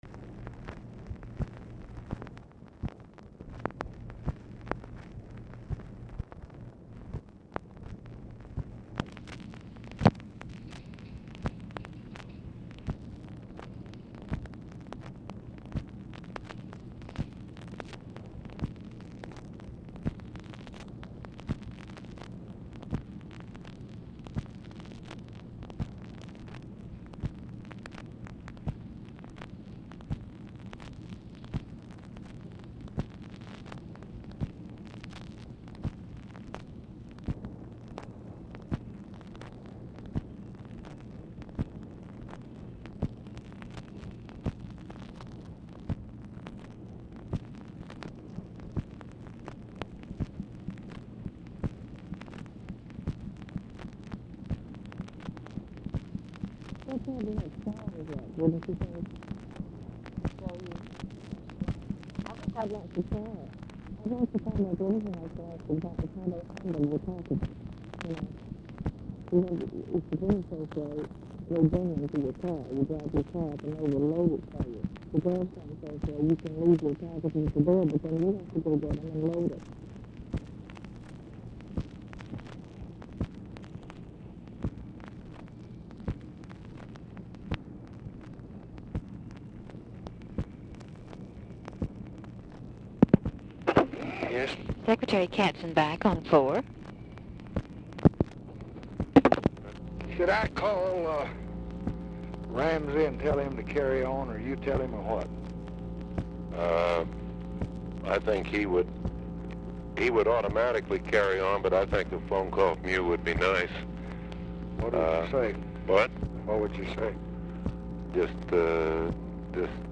Telephone conversation # 10917, sound recording, LBJ and NICHOLAS KATZENBACH, 10/3/1966, 5:51PM | Discover LBJ
Format Dictation belt
Specific Item Type Telephone conversation